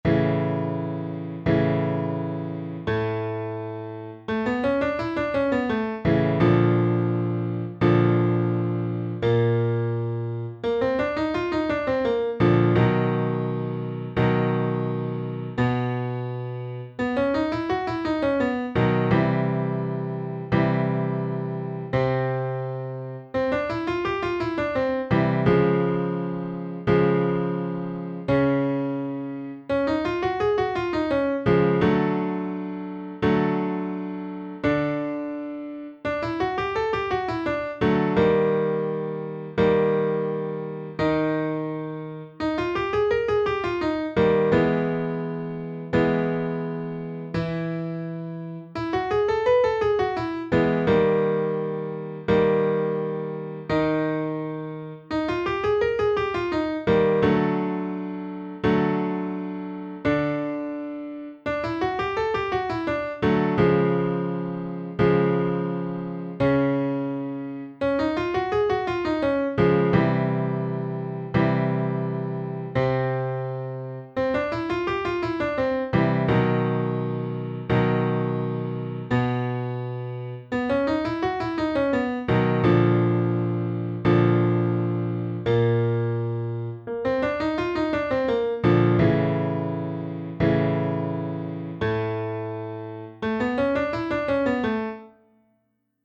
Śpiewamy
ćwiczenie „Baba ci koguta niesie tu” od dźwięku A2 do E3 powrót do A2